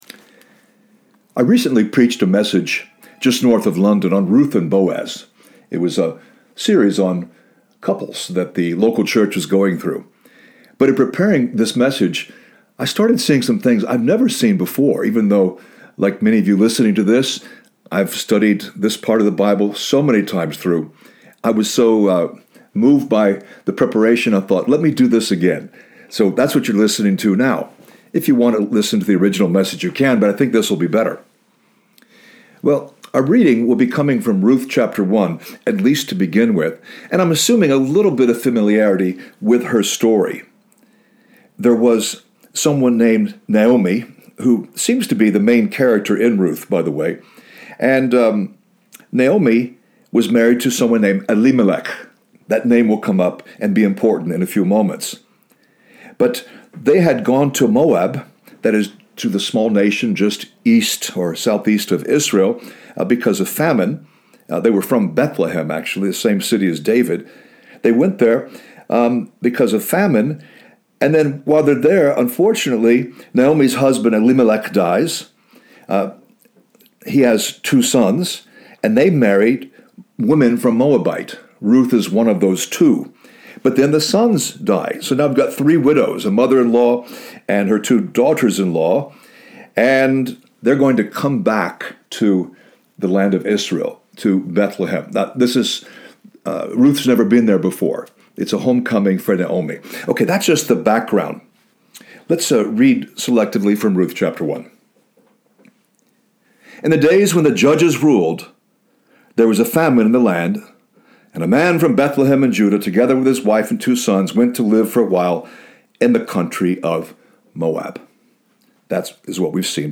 The talk is 19 minutes in length.